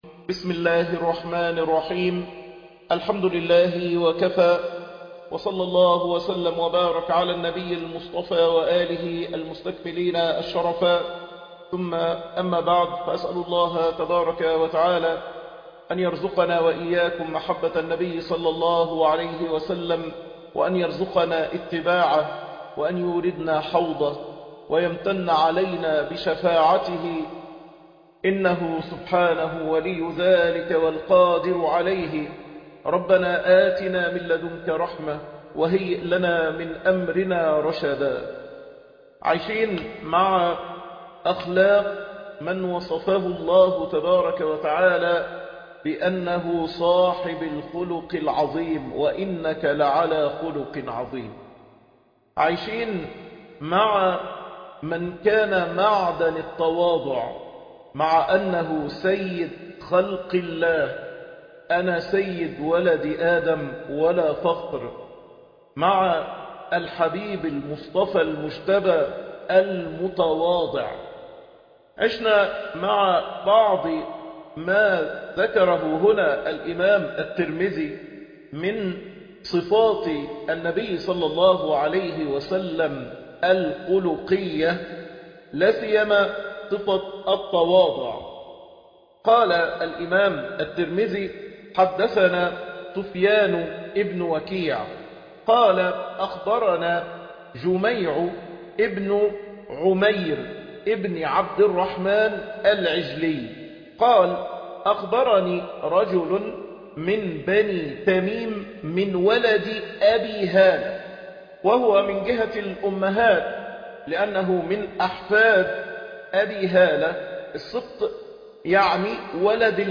شرح الشمائل المحمدية الدرس 38 عايش معاه صلى الله عليه وسلم